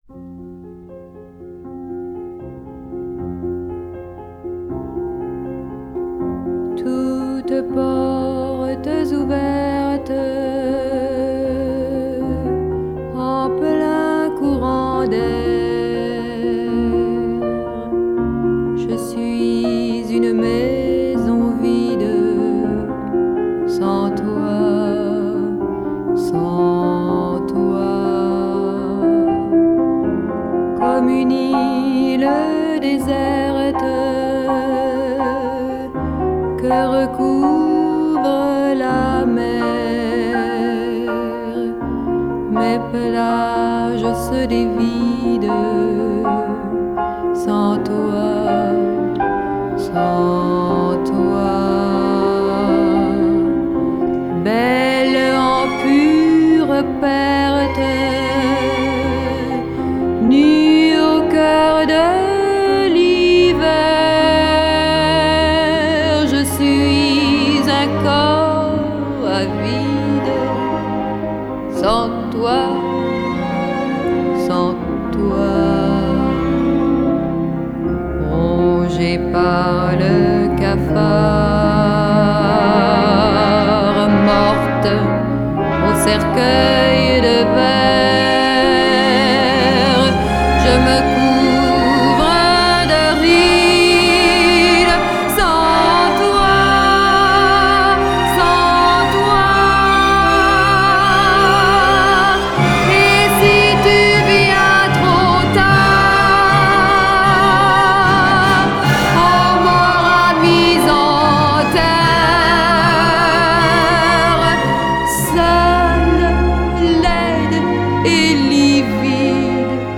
Жанр: Bossa Nova.